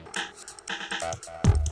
Today they are working on a techno-trance track.
The kick and the bass have a rather "deep"
created to be played in loops.